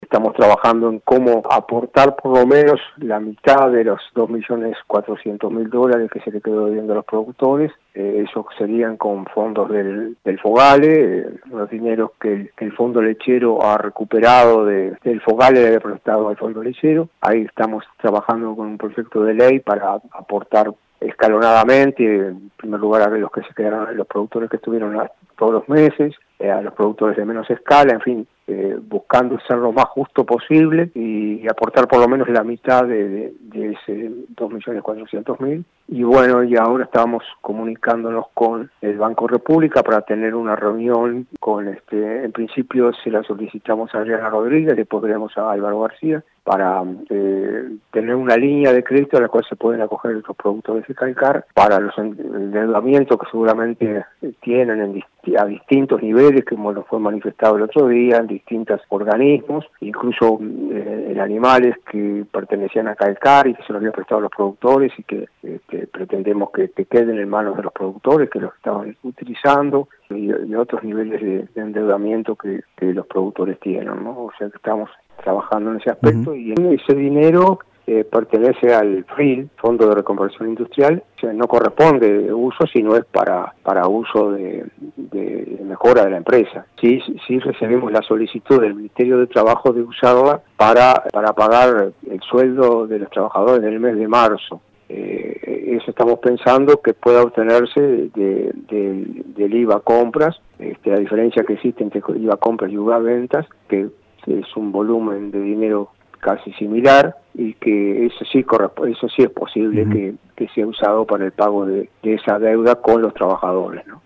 Dialogamos con el presidente de Inale, Ricardo de Izaguirre.